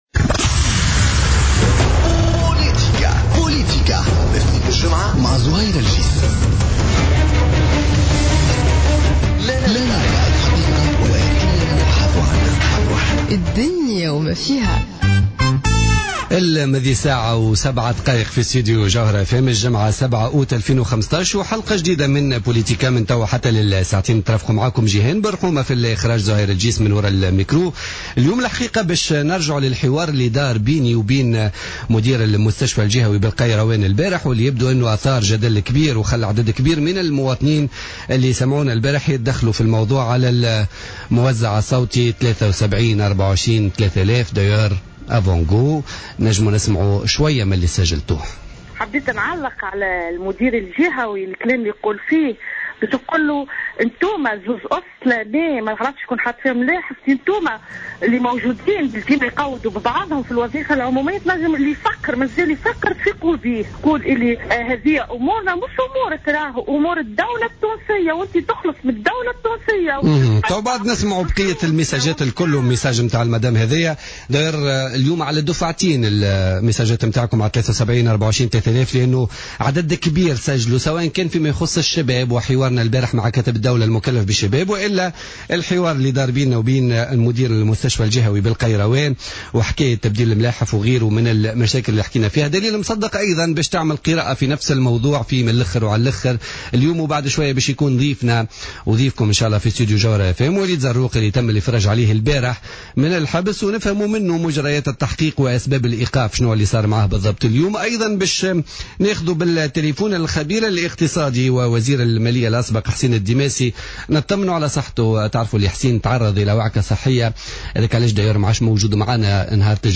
invité sur le plateau de Jawhara FM